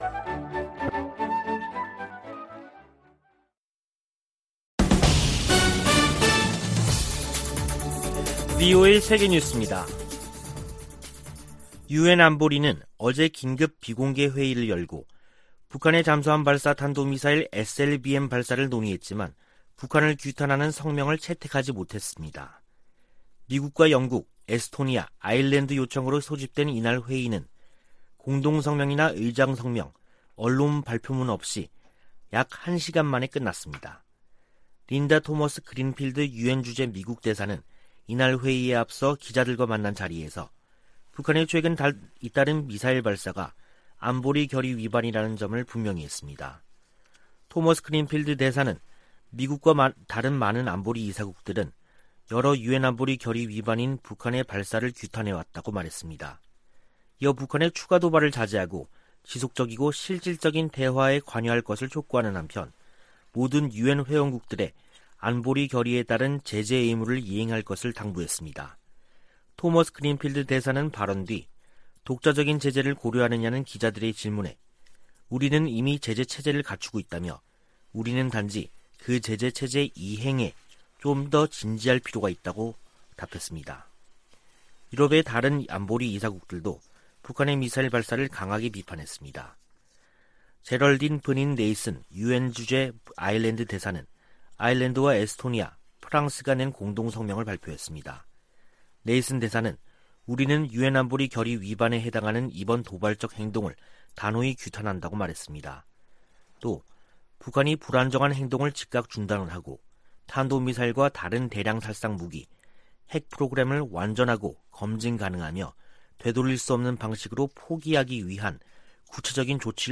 VOA 한국어 간판 뉴스 프로그램 '뉴스 투데이', 2021년 10월 21일 3부 방송입니다. 유엔 안보리가 북한 SLBM 규탄 성명을 내는데 실패했습니다. 독일과 영국이 북한의 미사일 시험 발사를 규탄하며, 완전하고 검증 가능하며 되돌릴 수 없는 핵포기를 촉구했습니다. 북한은 SLBM 시험발사가 미국을 겨냥한 게 아니라고 주장했습니다.